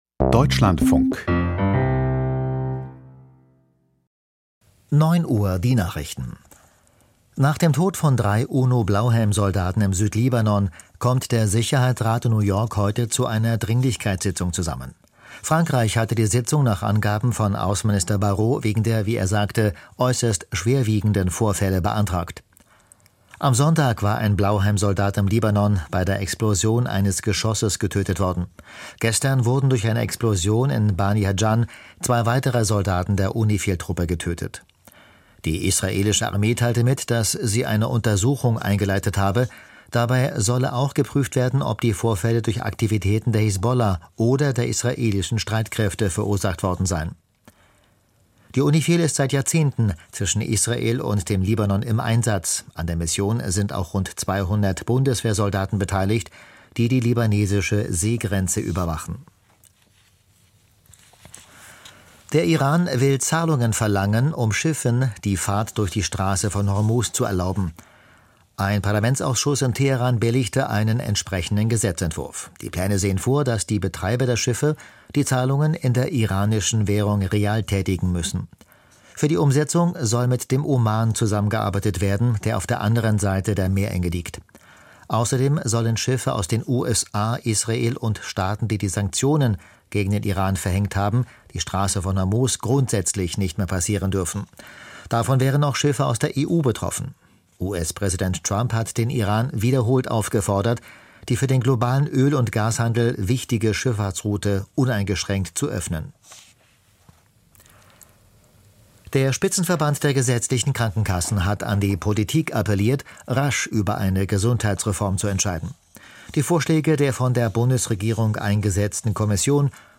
Die Nachrichten vom 31.03.2026, 09:00 Uhr
Aus der Deutschlandfunk-Nachrichtenredaktion.